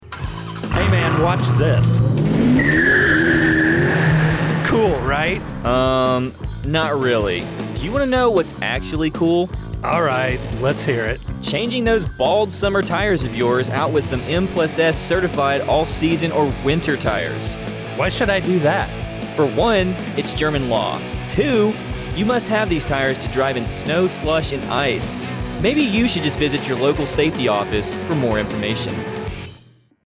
U.S. Army Garrison Wiesbaden informs the community on the importance of winter tire safety Nov. 9, 2022, Wiesbaden, Hessen, DE. The audio spot was recorded at the AFN Wiesbaden studio on Clay Kaserne, Nov. 9, 2022.